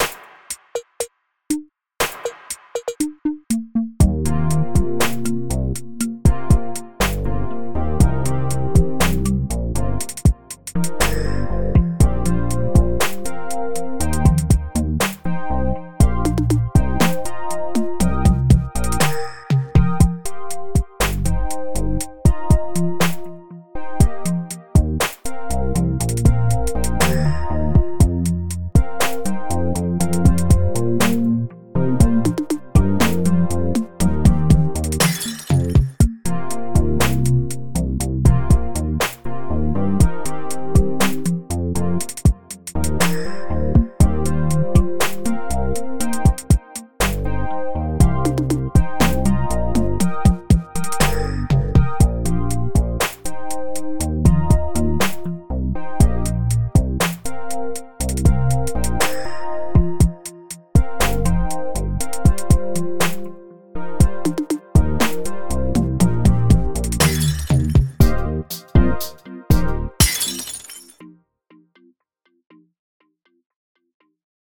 Bass, some E-piano thing, and a cheap plastic toy (no, the patch is actually called plastic toy) doing a marimba kind of thing, with electronic drums in the background.
So, because I'm feeling very uninspired at the moment, I hooked a MIDI interface up to move, and another one to my computer, recorded this MIDI file into Move, added a drum track, and now I'm posting it here. The Melody is unedited from it's original, except for one note that I didn't like.